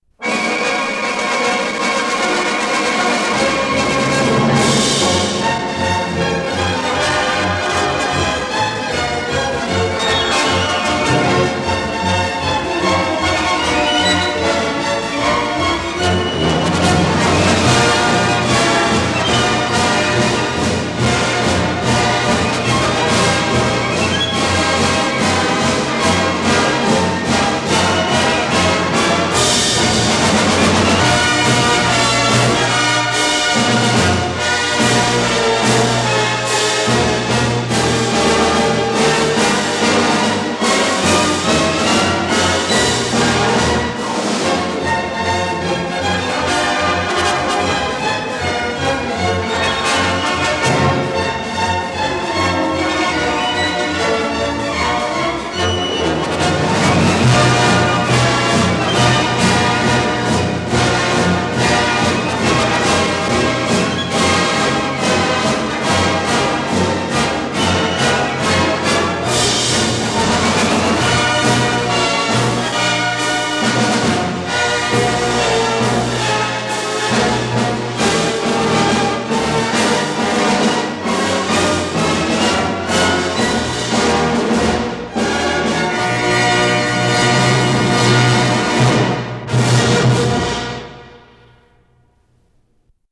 Скорость ленты38 см/с
Тип лентыШХЗ Тип 2